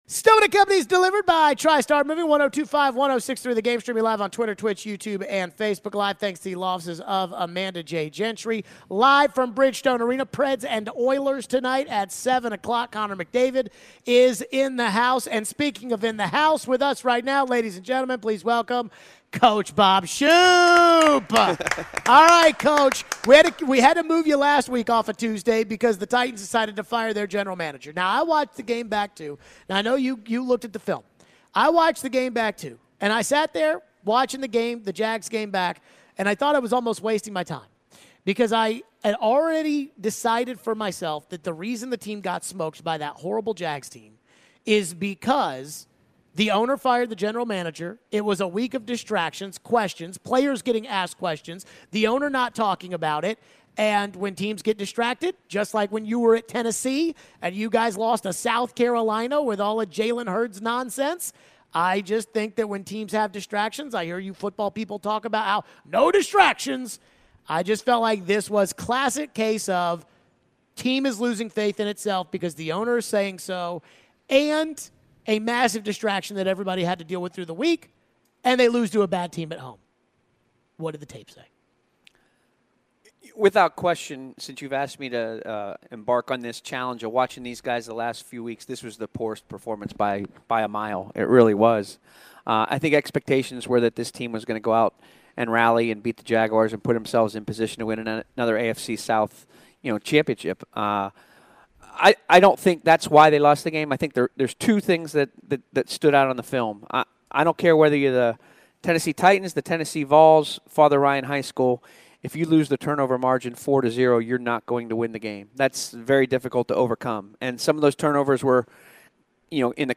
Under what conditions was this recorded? joins the show in studio this hour.